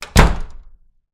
DoorClose3.wav